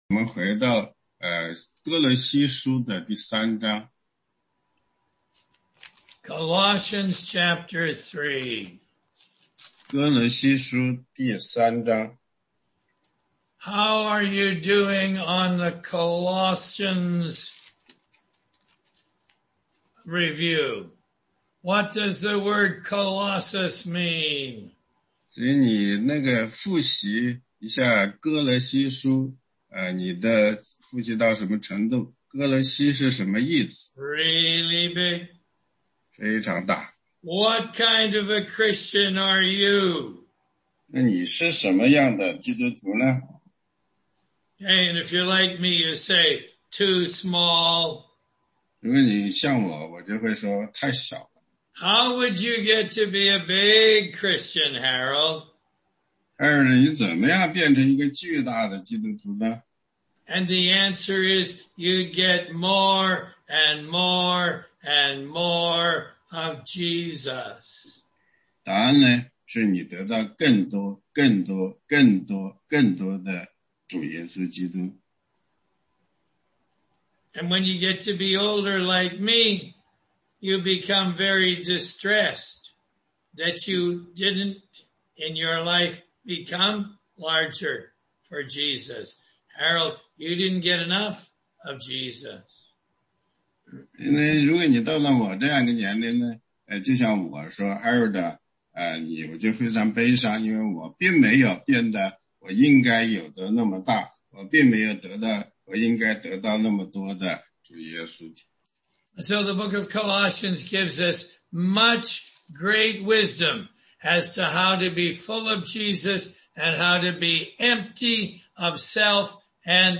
16街讲道录音 - 歌罗西书3章18节-4章6节
答疑课程